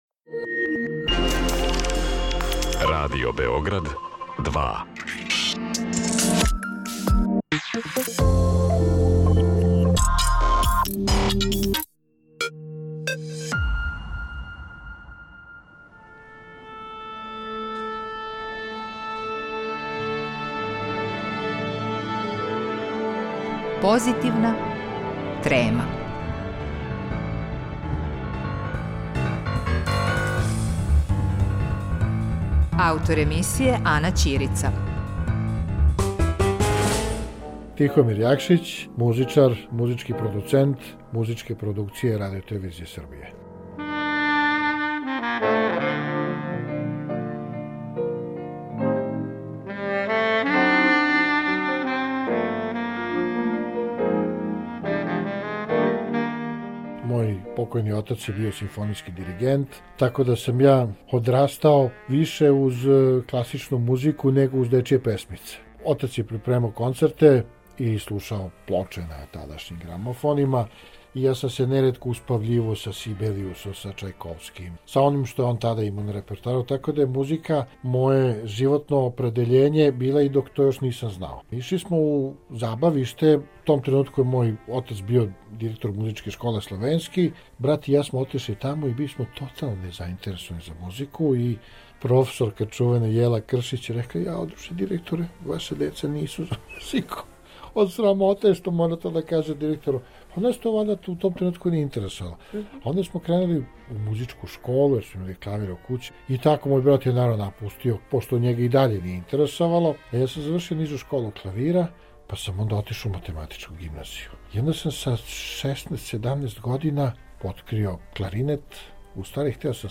Емитујемо разговор са саксофонистом